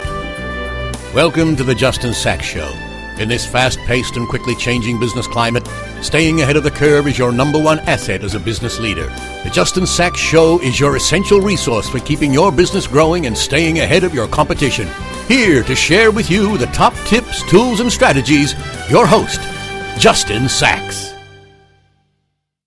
Rode NT-1A Microphone, Focusrite interface.
BaritoneDeepLow